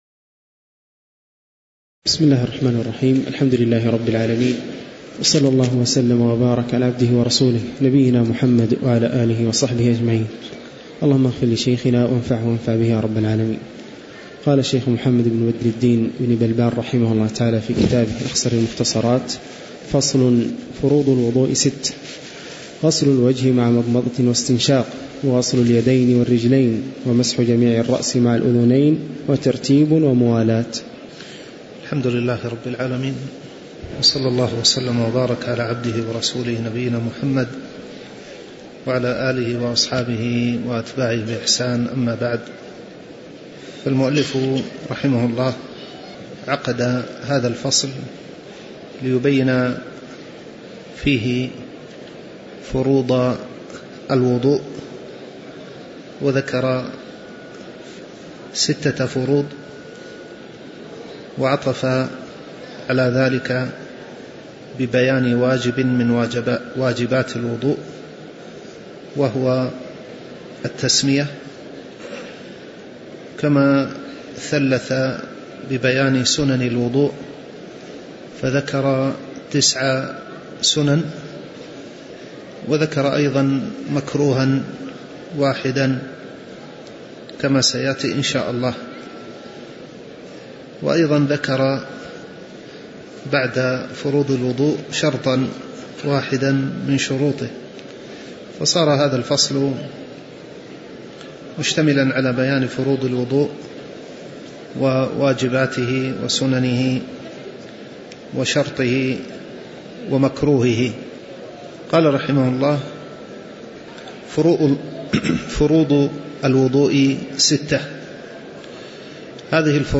تاريخ النشر ٢٥ صفر ١٤٣٩ هـ المكان: المسجد النبوي الشيخ